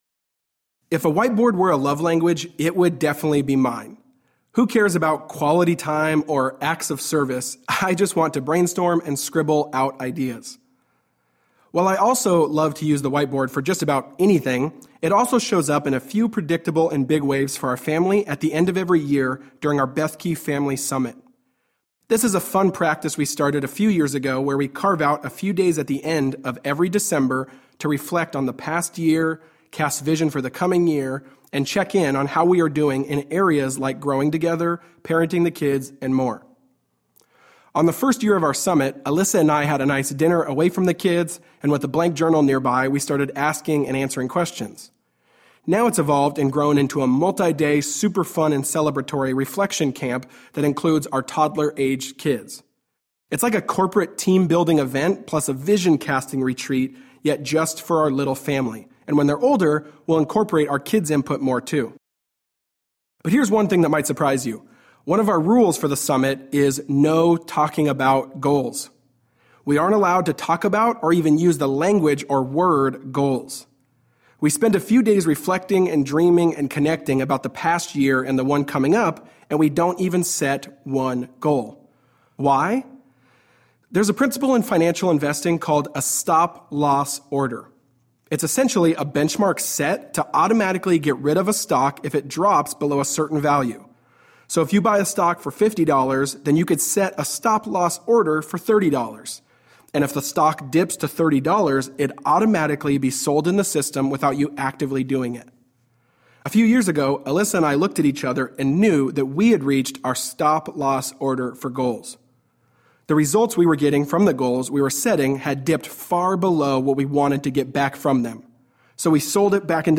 To Hell with the Hustle Audiobook
Narrator
5.5 Hrs. – Unabridged